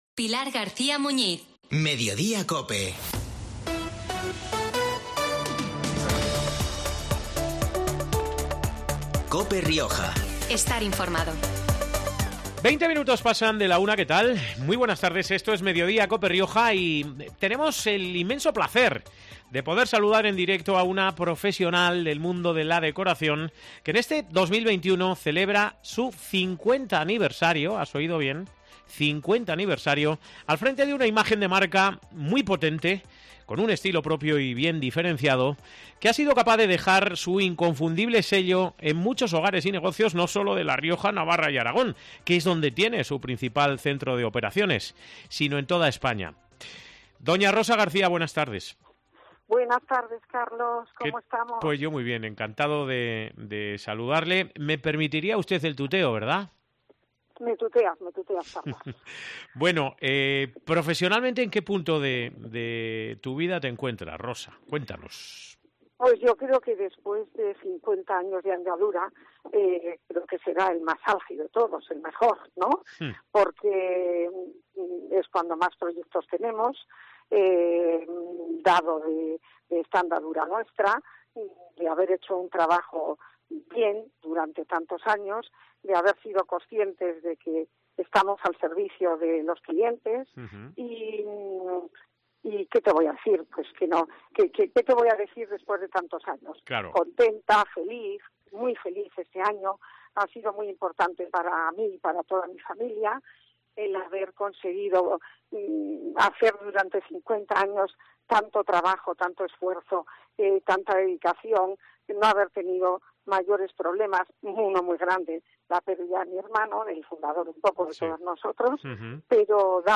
Este viernes, 3 de diciembre, hemos podido saludar en directo, en COPE Rioja, a una profesional del mundo de la decoración, que en este 2021 celebra su 50 aniversario al frente de una imagen de marca muy potente, con un estilo propio y bien diferenciado, que ha sido capaz de dejar su inconfundible sello en muchos hogares y negocios no sólo de La Rioja, Navarra y Aragón, que es donde tiene su principal centro de operaciones, sino en toda España.